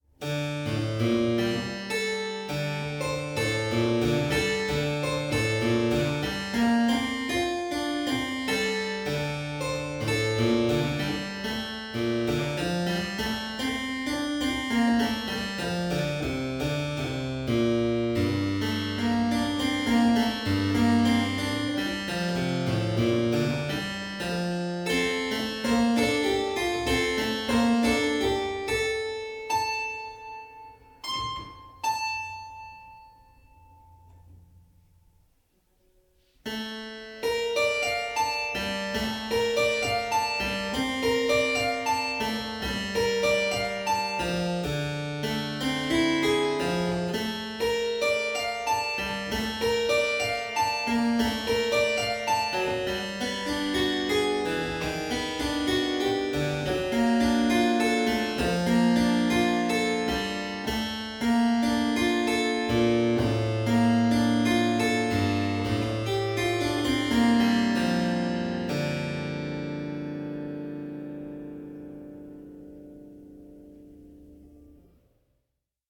HARPSICHORD MUSIC